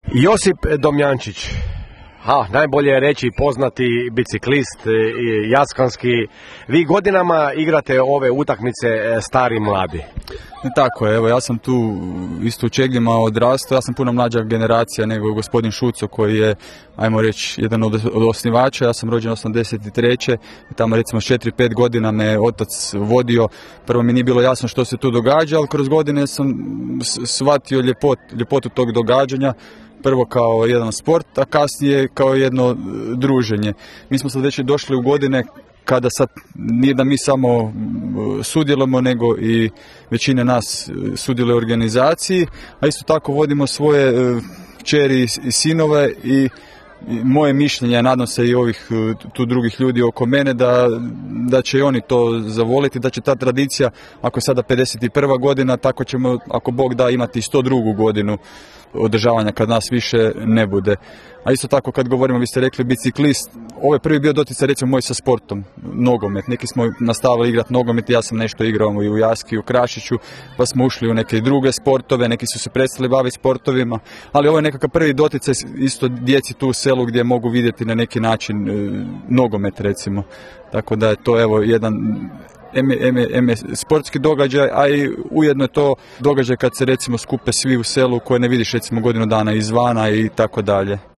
Bili smo u Čegljima na susretu starih i mladih